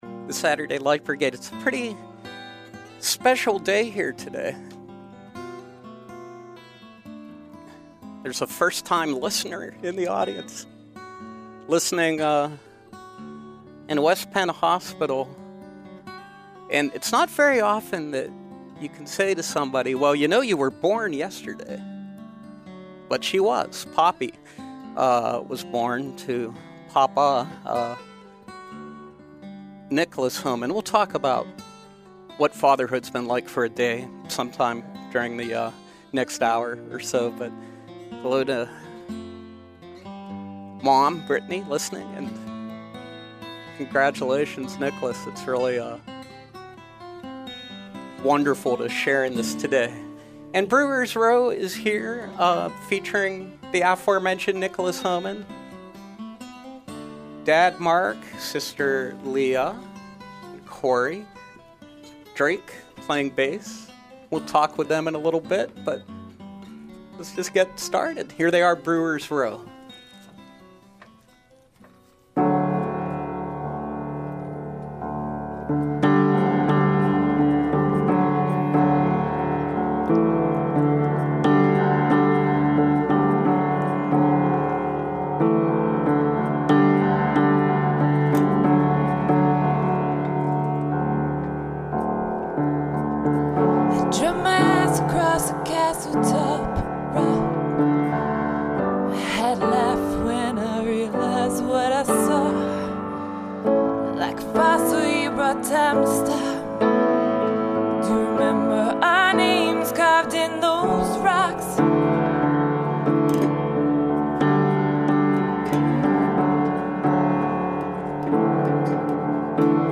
The family that sings together stays together!
rootsy folk pop/rock